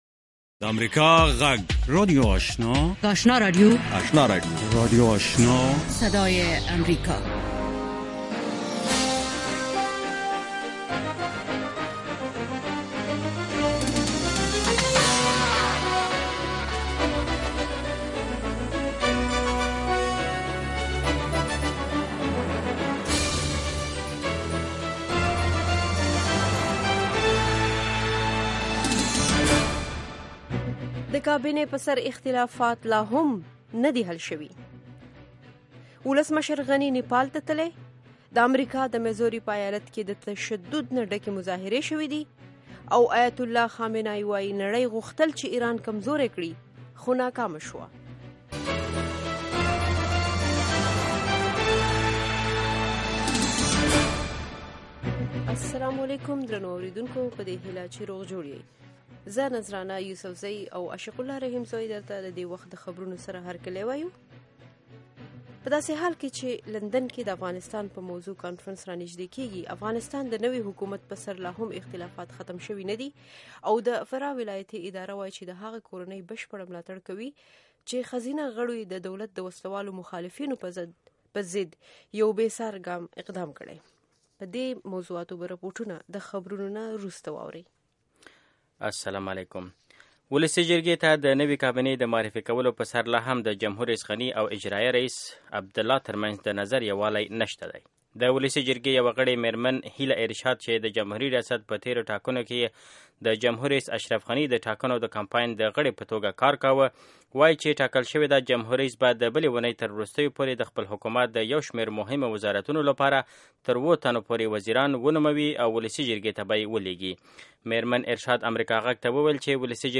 یو ساعته پروگرام: د ورځې د مهمو سیاسي، اجتماعي او نورو مسایلو په اړه له افغان چارواکو او کارپوهانو سره خبرې کیږي. د اوریدونکو پوښتنو ته ځوابونه ویل کیږي. ددغه پروگرام په لومړیو ١٠ دقیقو کې د افغانستان او نړۍ وروستي خبرونه اورئ.